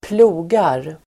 Uttal: [²pl'o:gar]